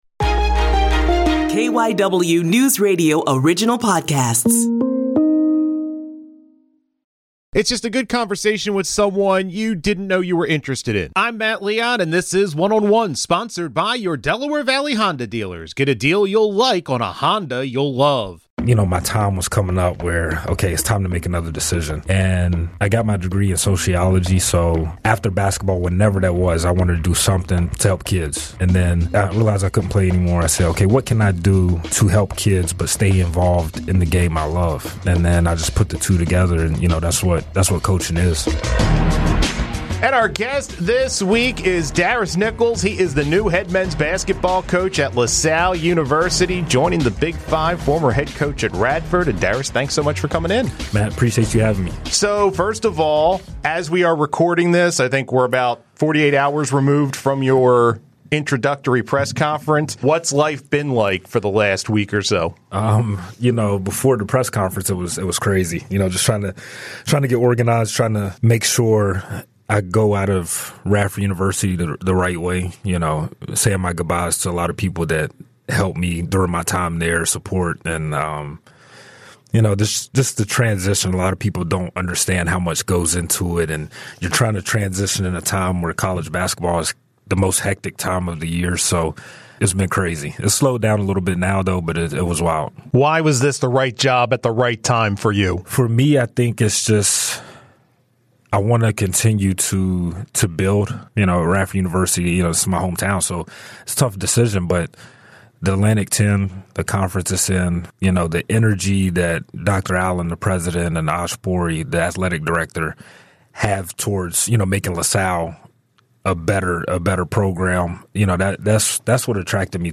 in studio to talk about his life in basketball